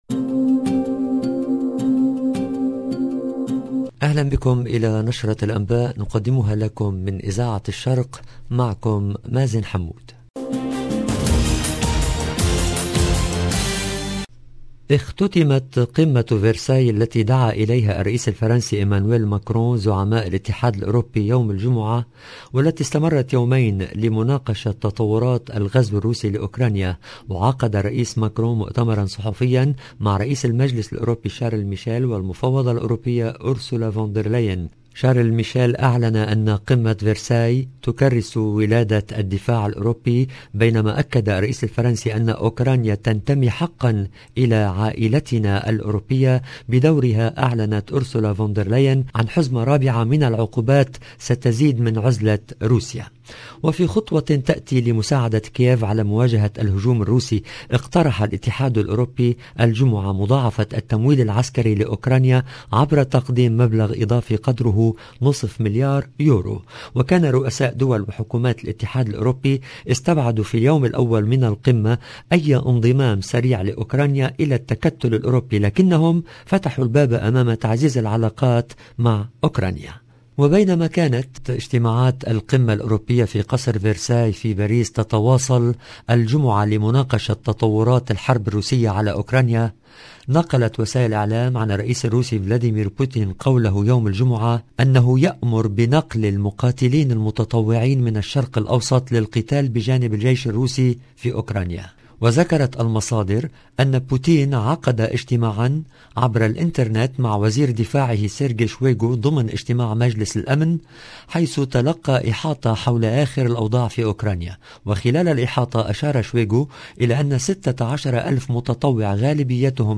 LE JOURNAL DU SOIR EN LANGUE ARABE DU 11/03/22